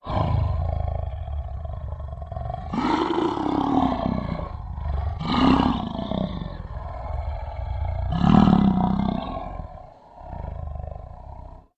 دانلود صدای شیر سلطان جنگل برای کودکان از ساعد نیوز با لینک مستقیم و کیفیت بالا
جلوه های صوتی